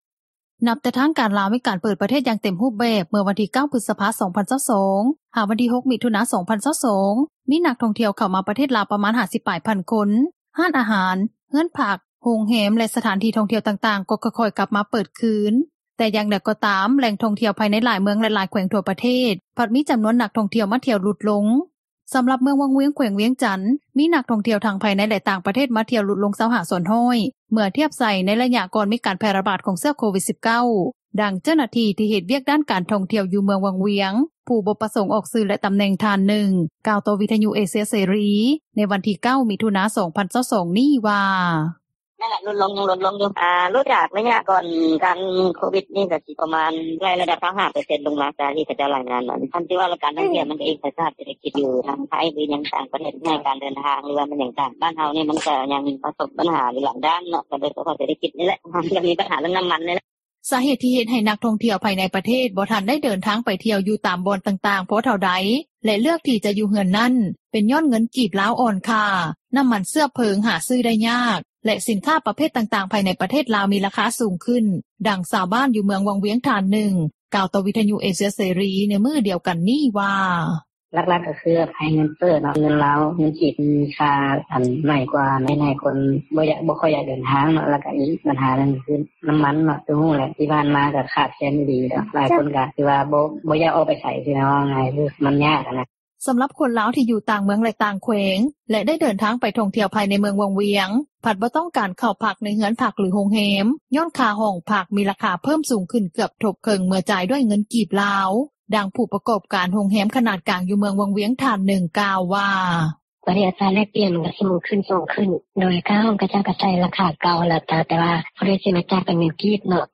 ດັ່ງຊາວບ້ານ ຢູ່ເມືອງວັງວຽງ ທ່ານນຶ່ງ ກ່າວຕໍ່ວິທຍຸເອເຊັຽເສຣີ ໃນມື້ດຽວກັນນີ້ວ່າ:
ດັ່ງຜູ້ປະກອບການໂຮງແຮມ ຂນາດນ້ອຍ ຢູ່ເມືອງວັງວຽງ ທ່ານນຶ່ງ ກ່າວວ່າ: